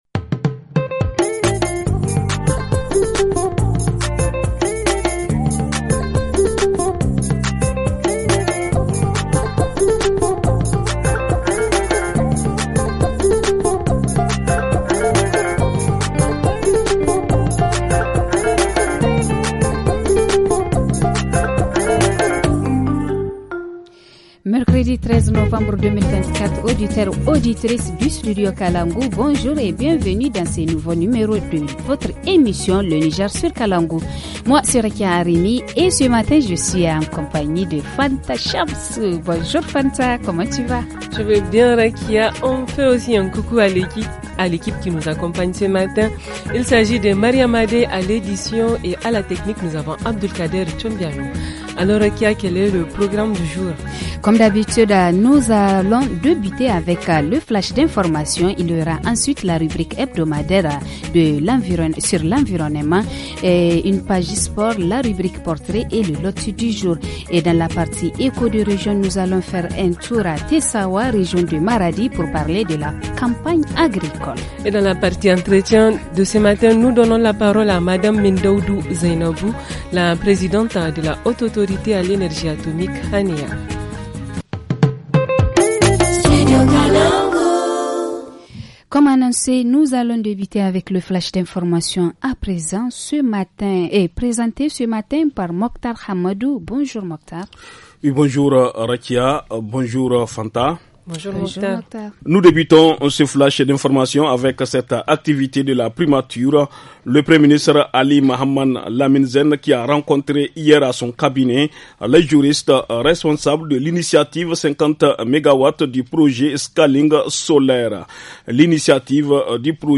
Dans l’émission de mercredi : entretien avec Mme Zeinabou, présidente de la Haute Autorité à l’Énergie Atomique • En reportage région, l’importance de la communication parents-enfants à Maradi • Dans la rubrique hebdo, retour sur la marche pour le climat organisée à Niamey par l’Association des Jeunes Volontaires pour l’Environnement.